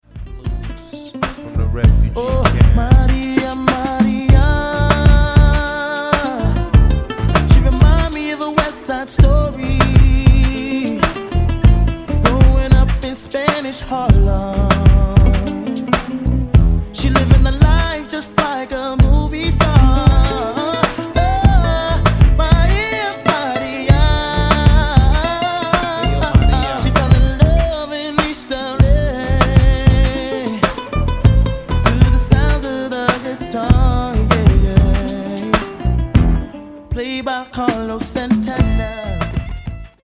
• Качество: 128, Stereo
гитара
RnB
Latin Pop